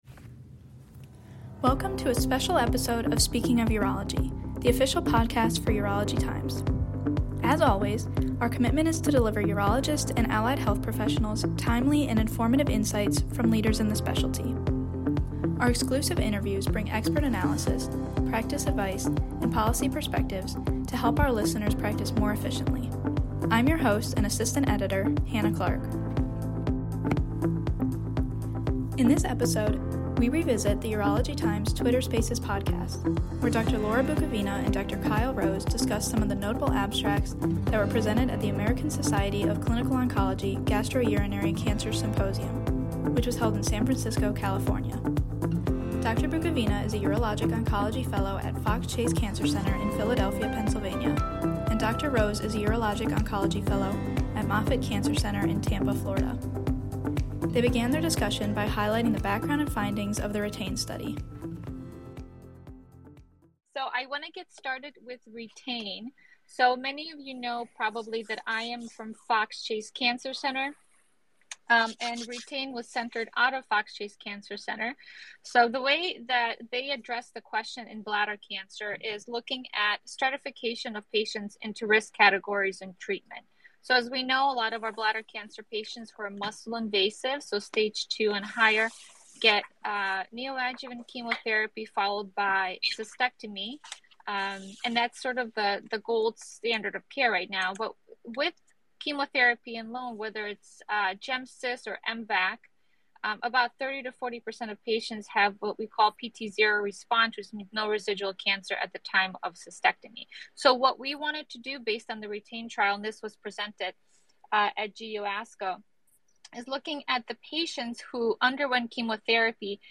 during the inaugural live Twitter Spaces event held by Urology Times